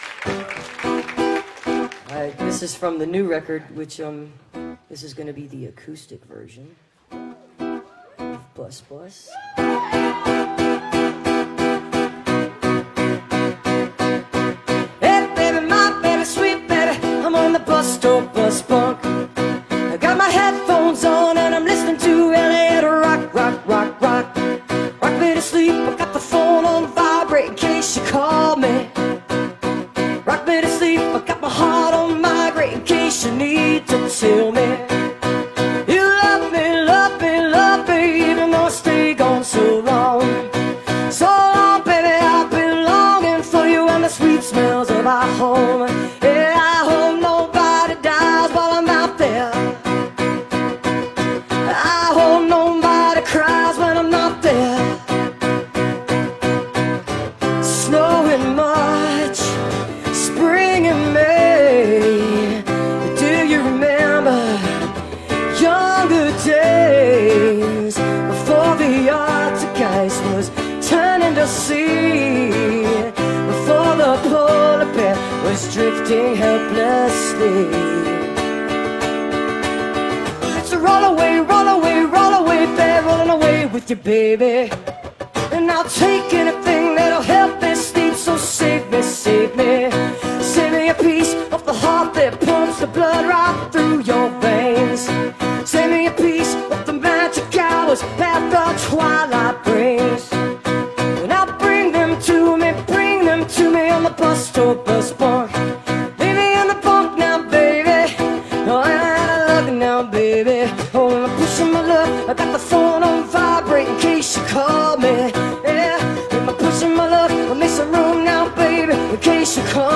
(captured from a youtube video)